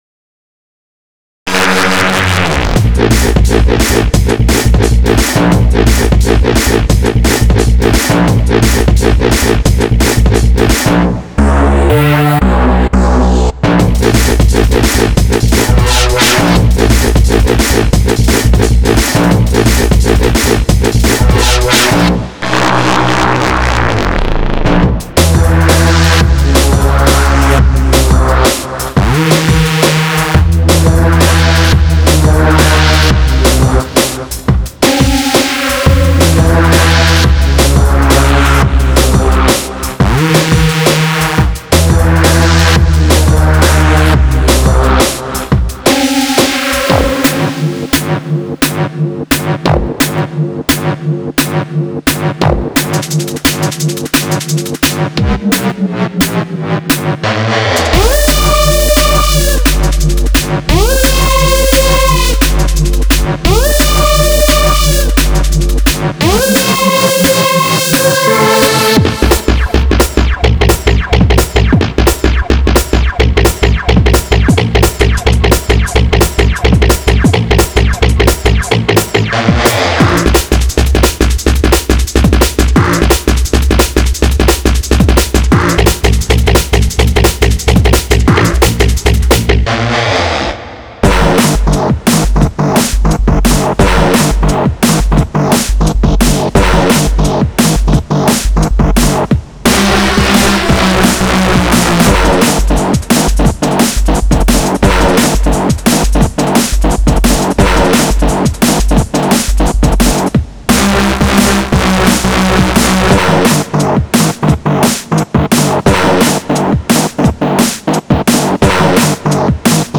30 Breakbeats
20 Drum Loops
15 Bassline Loops
15 Reese Bass